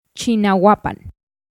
Chignahuapan Municipality (Spanish pronunciation: [tʃiɣnaˈwapan]